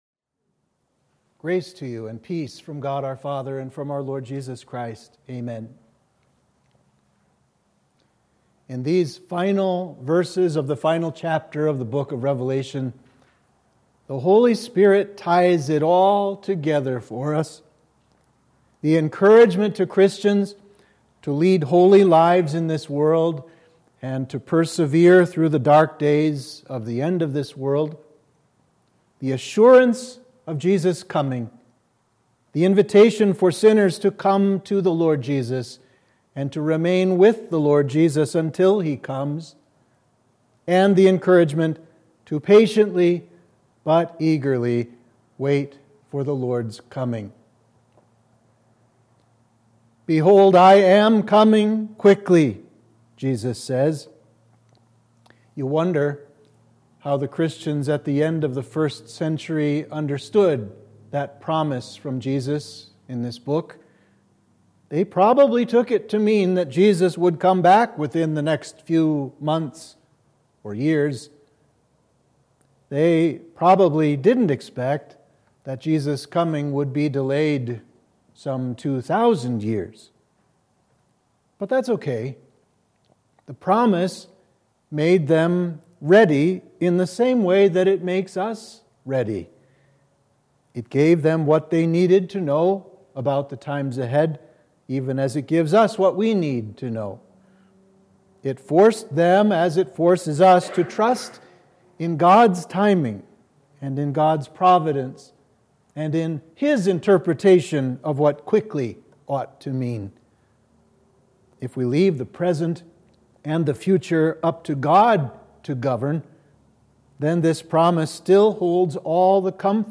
Sermon for Midweek of Trinity 27